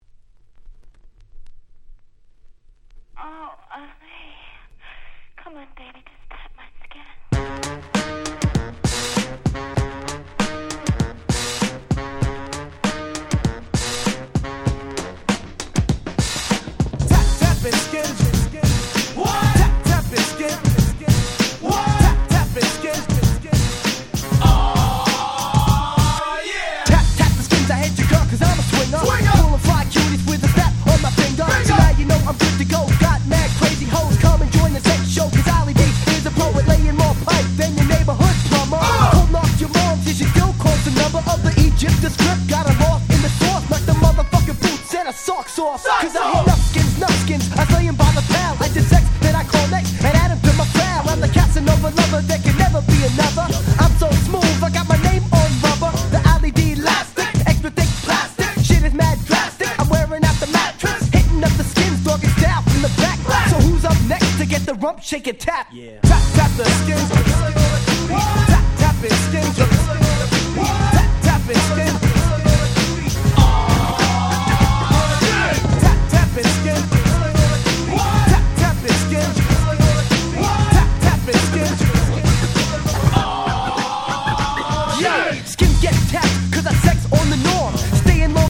93' Very Nice Hip Hop !!
90's ニュースクール Boom Bap ブーンバップ